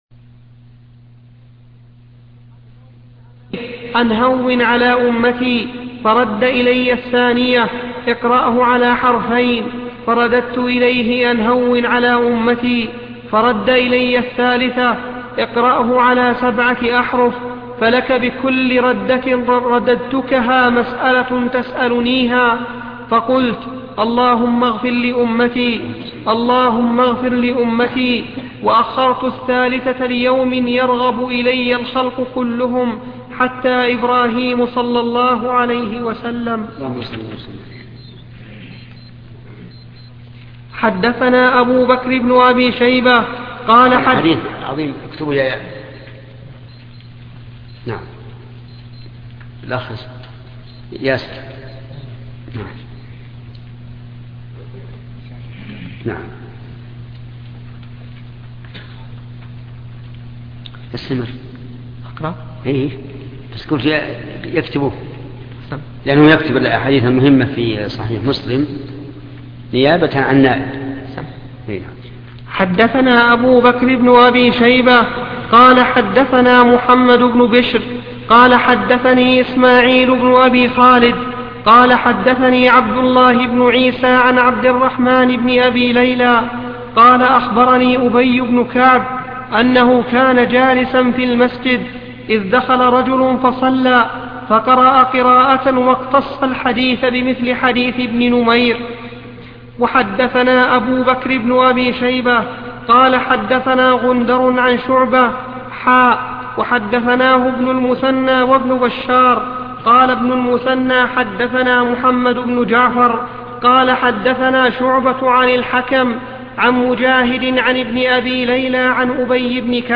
صحيح مسلم شرح الشيخ محمد بن صالح العثيمين الدرس 130